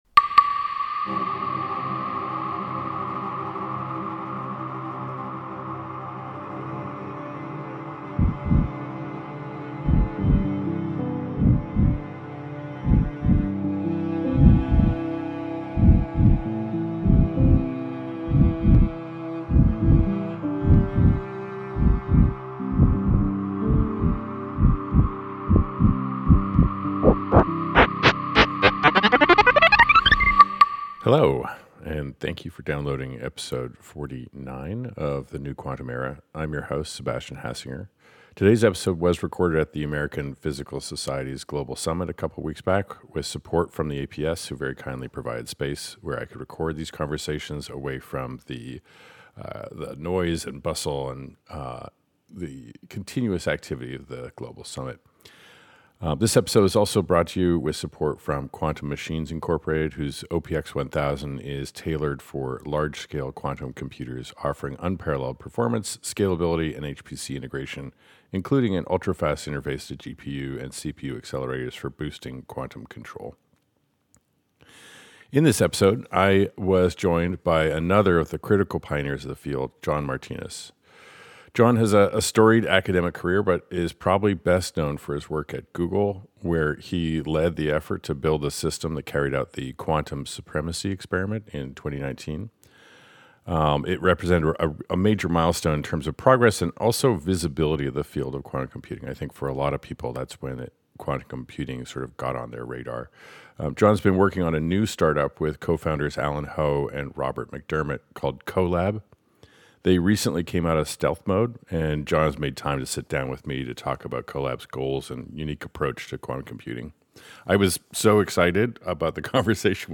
Another episode recorded at the APS Global Summit in March, today’s special guest is true quantum pioneer, John Martinis, co-founder and CTO of QoLab , a superconducting qubit company seeking to build a million qubit device. In this enlightening conversation, we explore the strategic shifts, collaborative efforts, and technological innovations that are pushing the boundaries of quantum computing closer to building scalable, million-qubit systems.